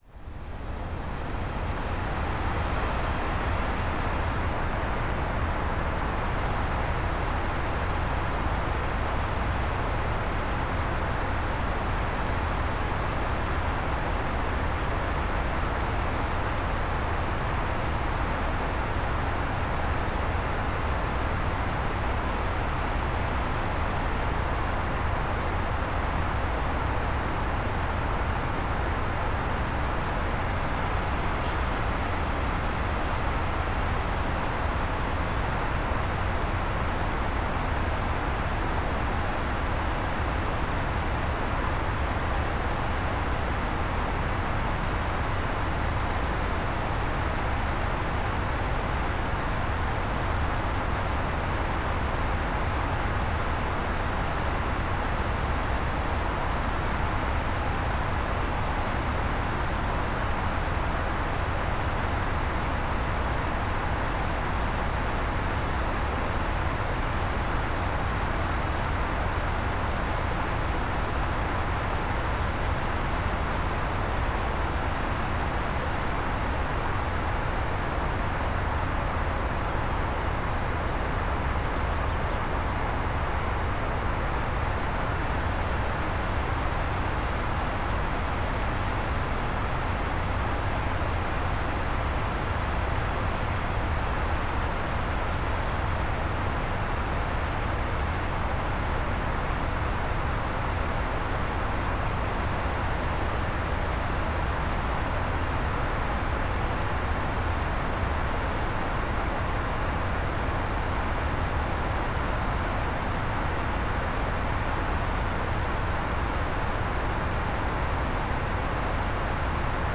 Wodospad ten, znany również jako Fjallfoss, spada kaskadowo z siedmiu poziomów, z których największy ma około 100 metrów i tworzy wodny welon.
Łączna wysokość wszystkich stopni wynosi około 150 metrów. Dynjandi w języku islandzkim oznacza „grzmiący”, co jest trafną nazwą, biorąc pod uwagę jego potężny i majestatyczny przepływ.
Dynjandi.mp3